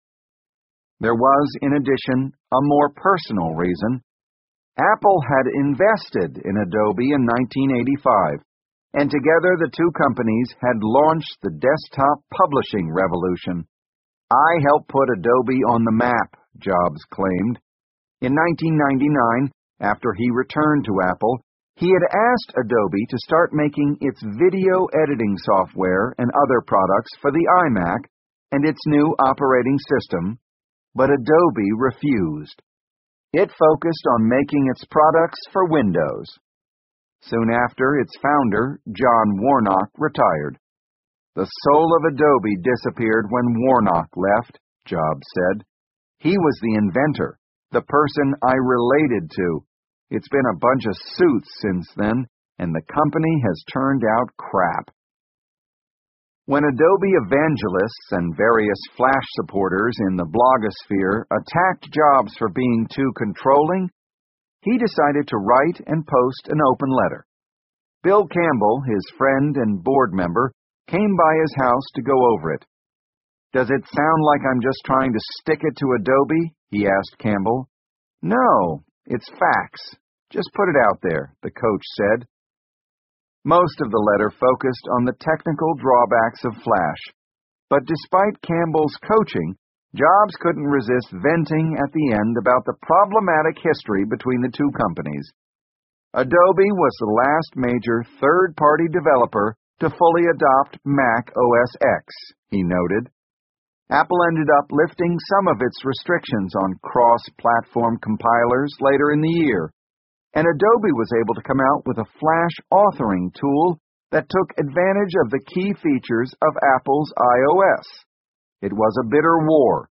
在线英语听力室乔布斯传 第716期:Flash 应用程序商店以及控制(2)的听力文件下载,《乔布斯传》双语有声读物栏目，通过英语音频MP3和中英双语字幕，来帮助英语学习者提高英语听说能力。
本栏目纯正的英语发音，以及完整的传记内容，详细描述了乔布斯的一生，是学习英语的必备材料。